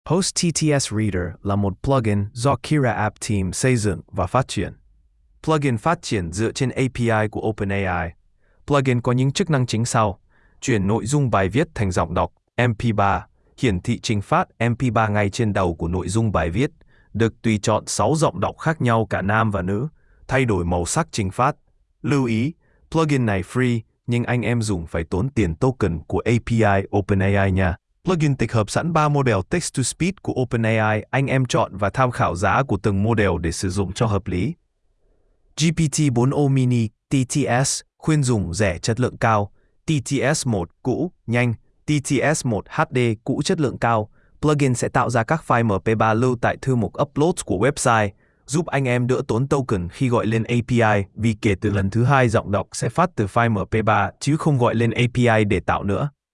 Plugin chuyển văn bản thành giọng nói miễn phí
• Giọng đọc tự nhiên